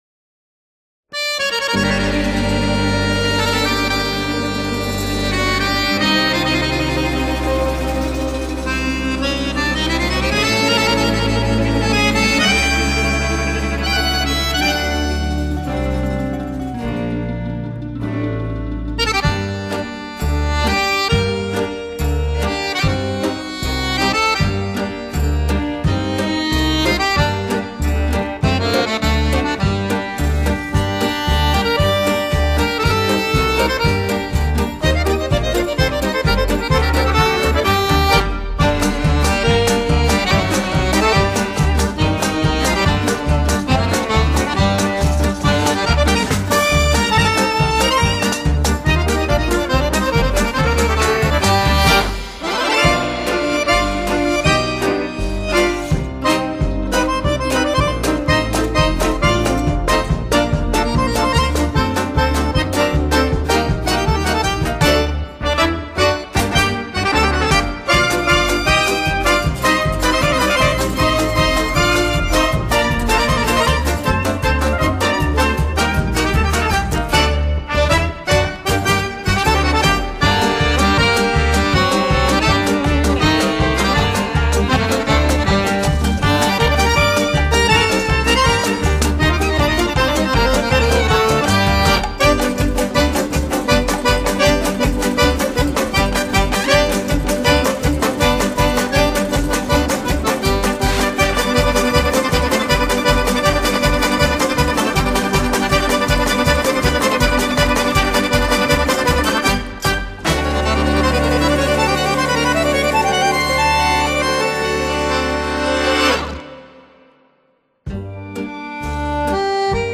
Genre: Classical